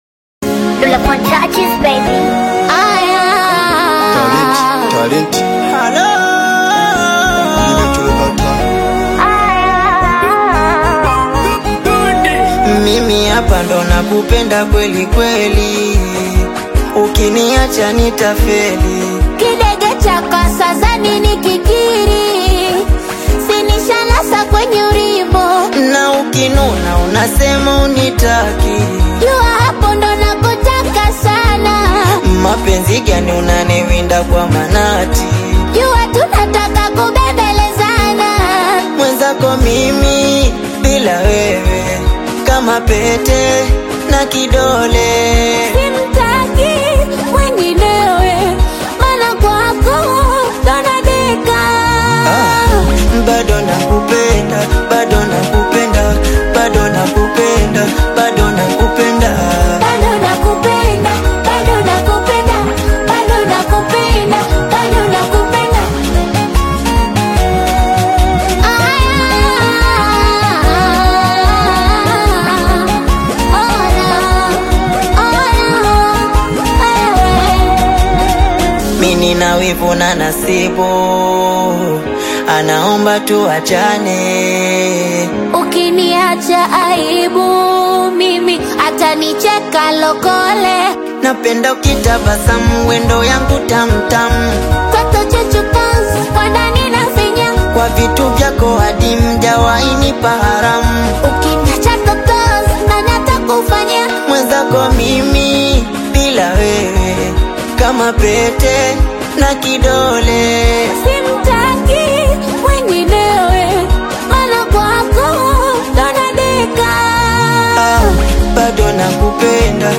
AudioSingeli
is a vibrant Afro-Pop/Singeli collaboration